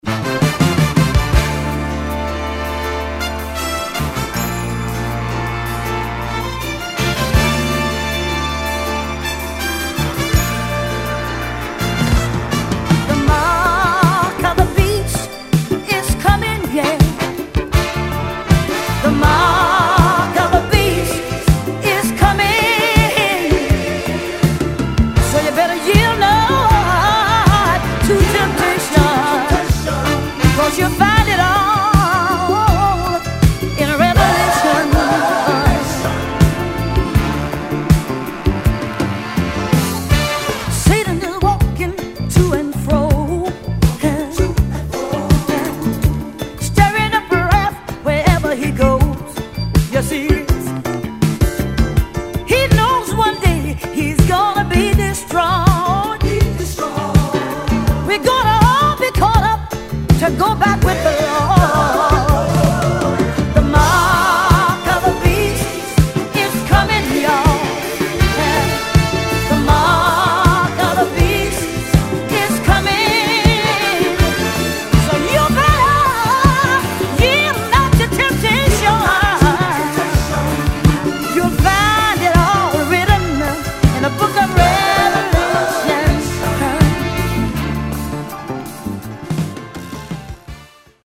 Gospel LP！